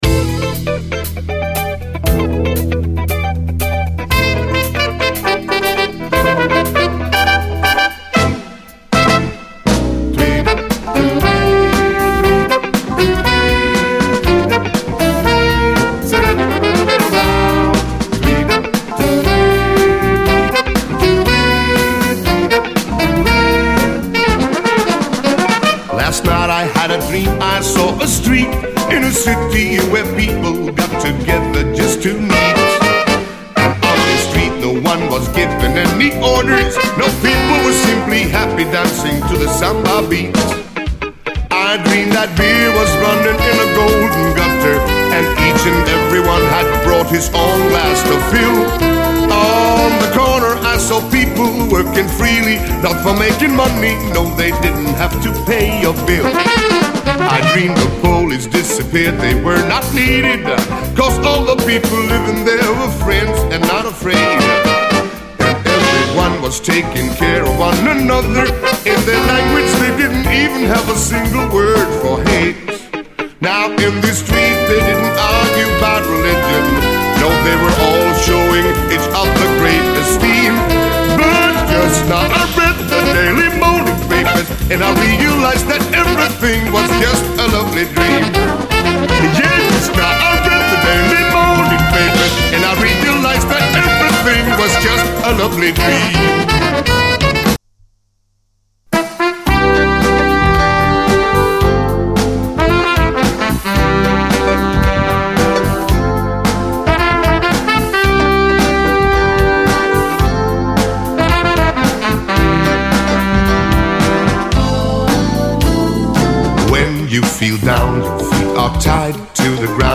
SOUL, JAZZ FUNK / SOUL JAZZ, 70's～ SOUL, JAZZ